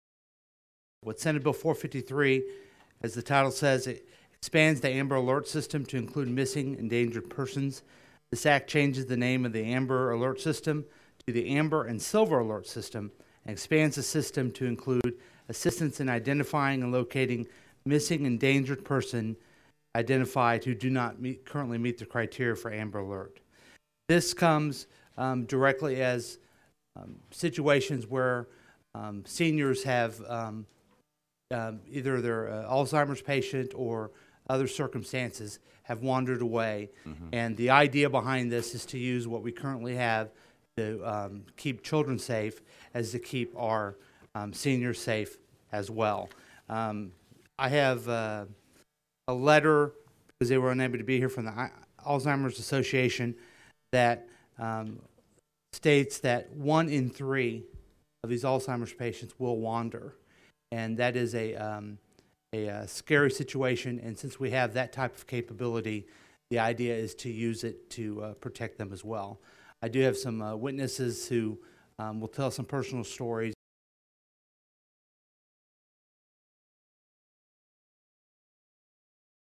Senator LeVota presents Senate Bill 453 to the Missouri Senate Seniors, Families and Children Committee. His proposal would expand the Amber alert system to include missing endangered persons.